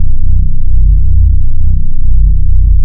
AmbDroneM.wav